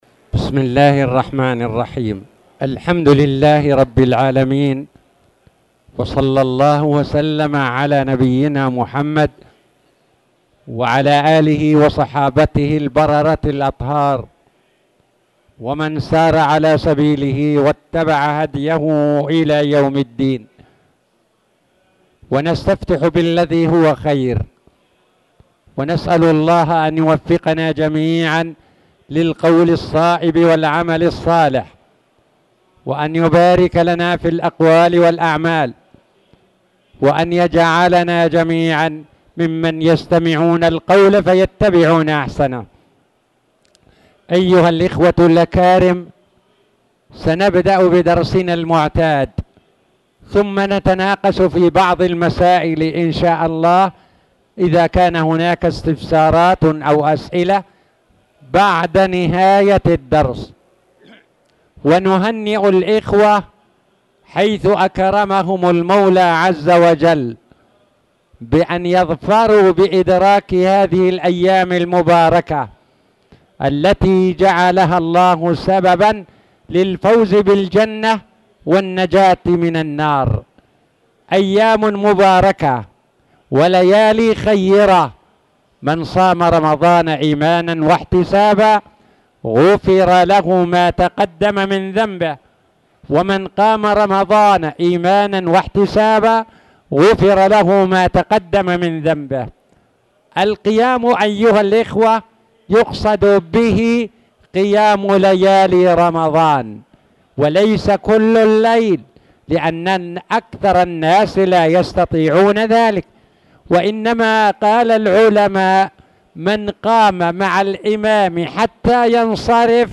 تاريخ النشر ٢ رمضان ١٤٣٧ هـ المكان: المسجد الحرام الشيخ